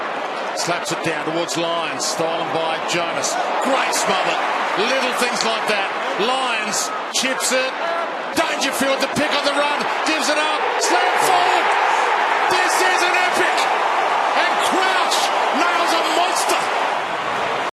Adelaide Oval erupted when Brad Crouch snapped the sealer in Showdown XXXVII